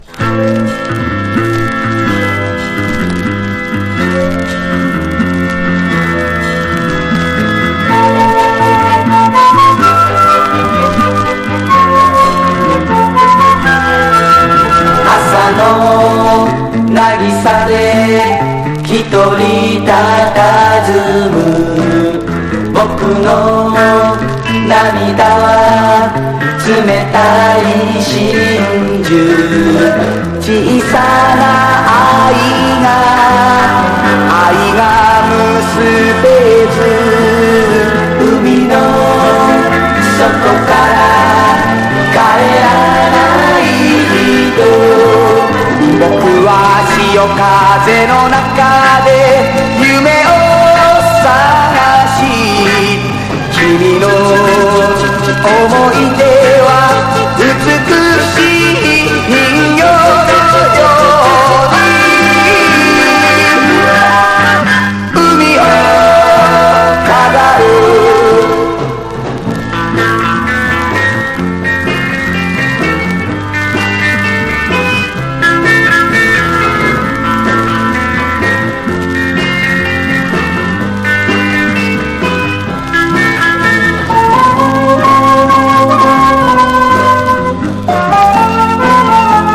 60-80’S ROCK
所によりノイズありますが、リスニング用としては問題く、中古盤として標準的なコンディション。